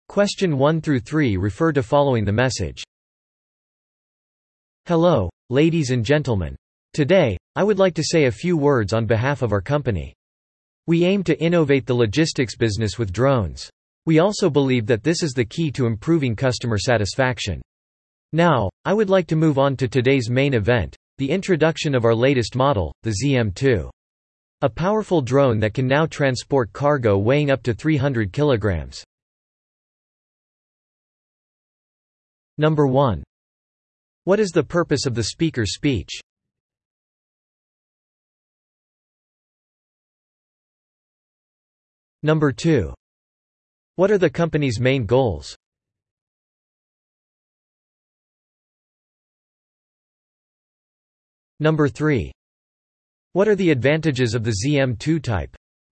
PART4のリスニング問題です。PART4はひとり語りなので、話の流れ、というより話の目的（main purpose)を意識して聞くといいかもしれません。